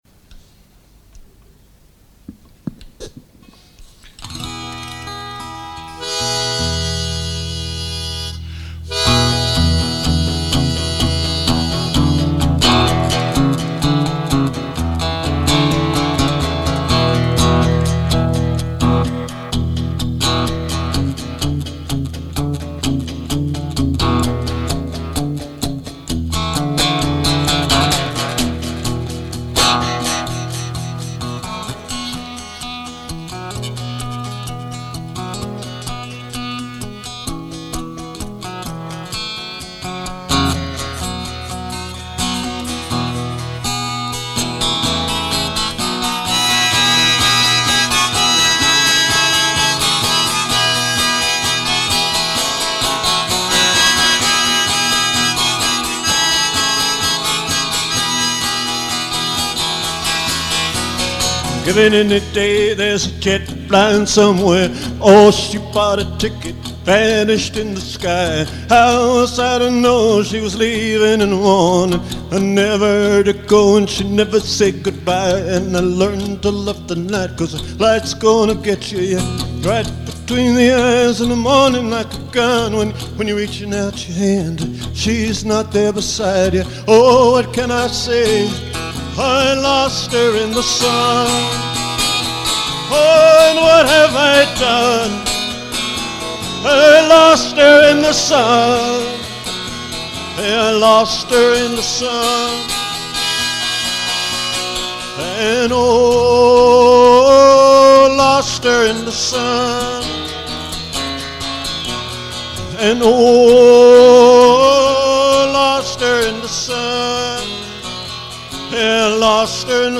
Here is a song from The Winning Post gig.
guitar, harmonica and vocals